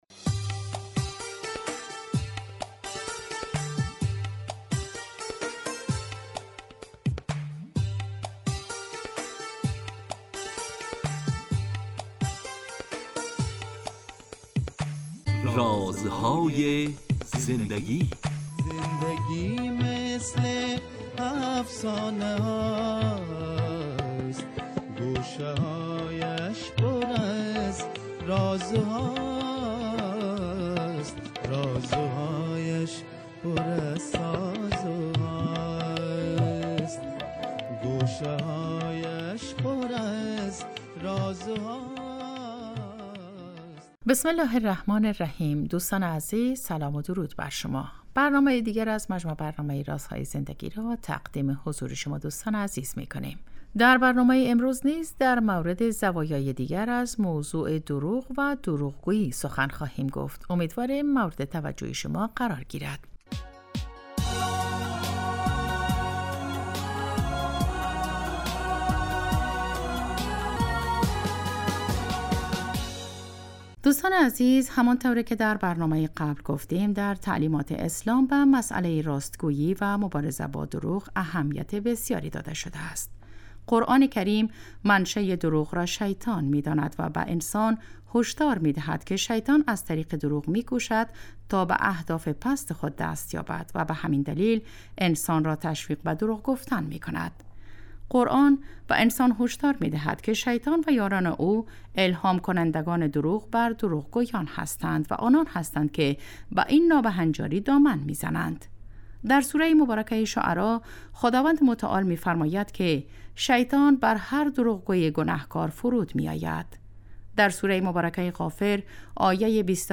با مجموعه برنامه " رازهای زندگی" و در چارچوب نگاهی دینی به سبک زندگی با شما هستیم. این برنامه به مدت 15 دقیقه هر روز ساعت 11:35 به وقت افغانستان از رادیو دری پخش می شود .